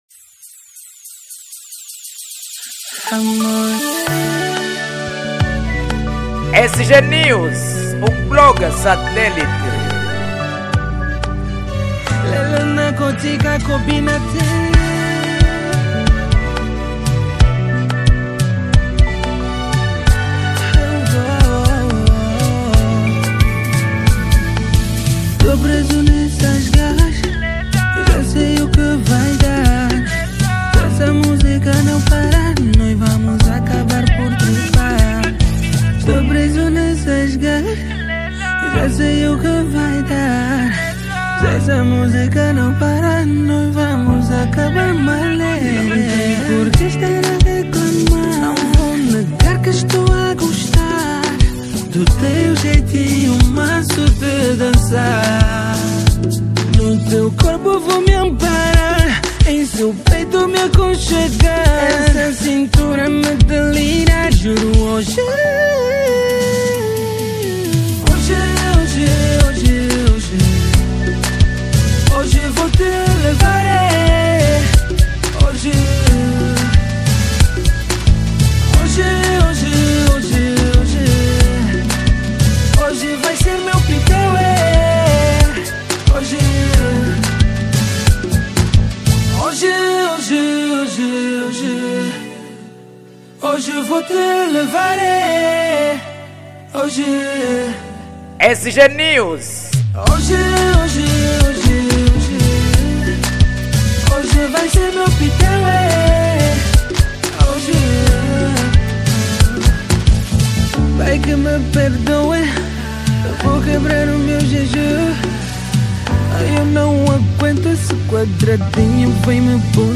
Género : Zouk